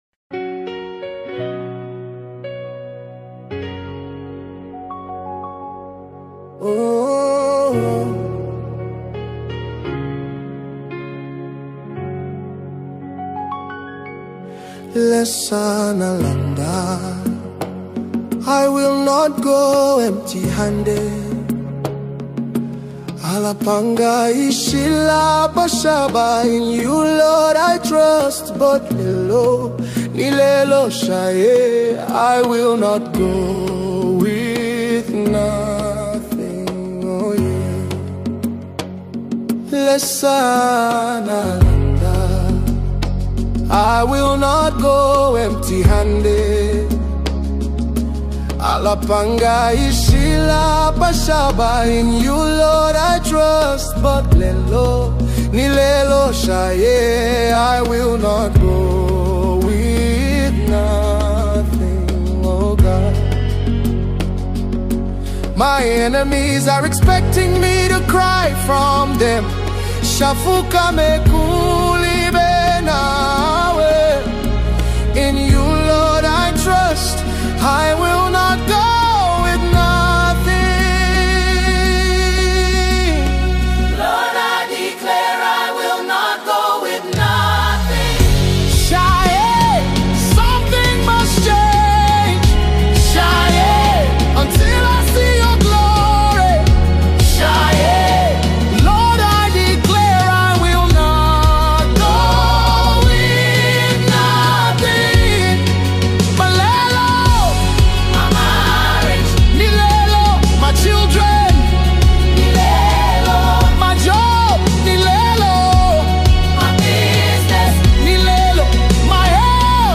New Zambian Gospel Song 2025
With its warm, uplifting melodies, emotive vocals